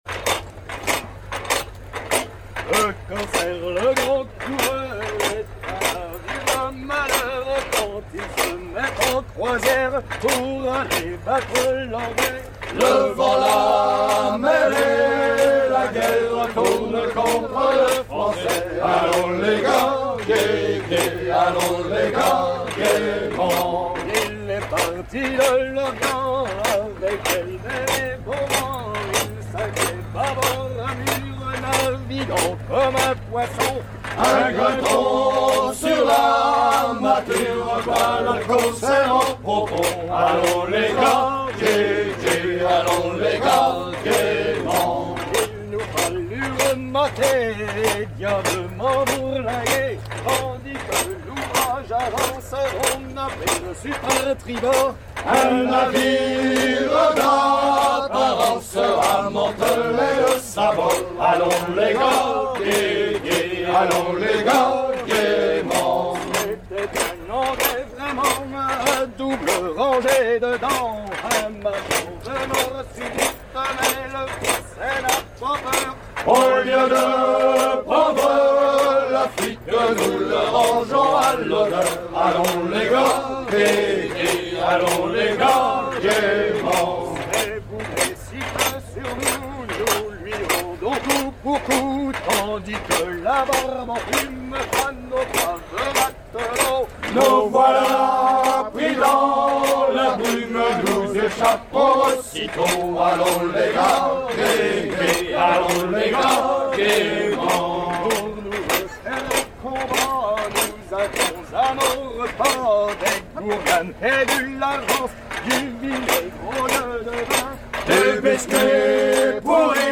à virer au cabestan
Genre strophique
Pièce musicale éditée